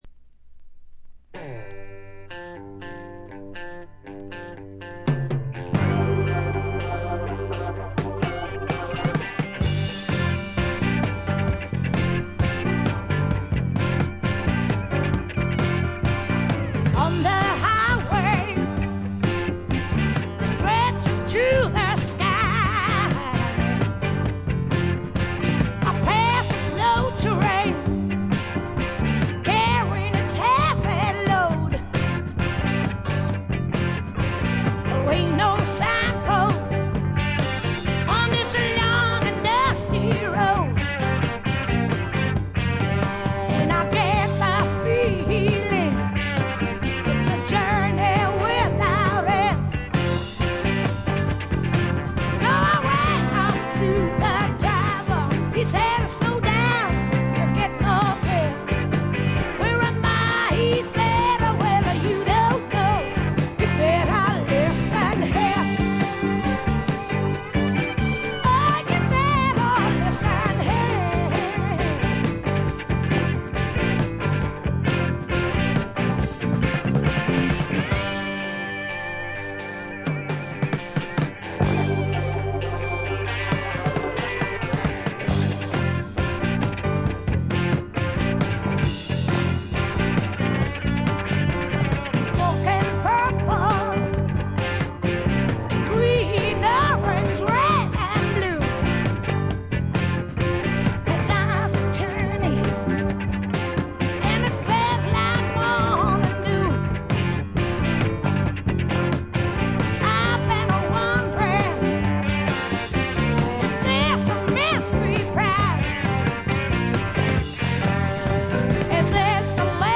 重厚なスワンプロックの大傑作です。
試聴曲は現品からの取り込み音源です。